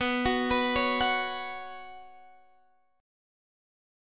Bsus2 chord